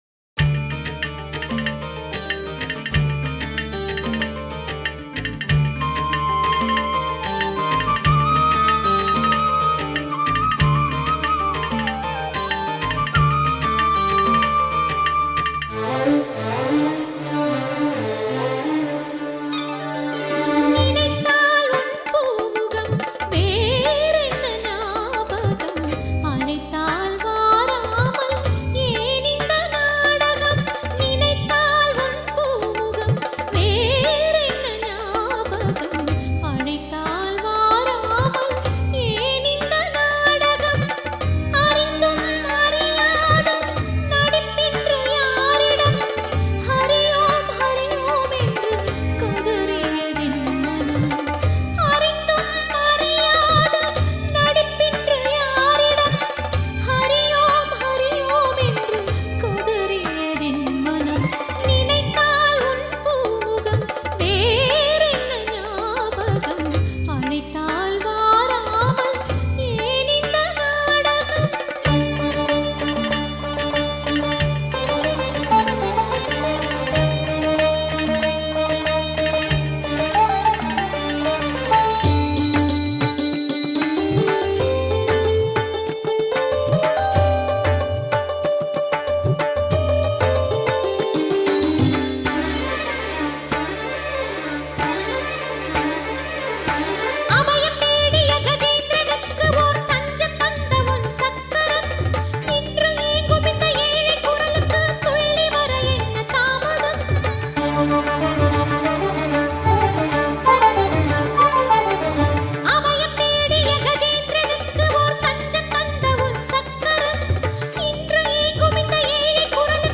Tamil devotional album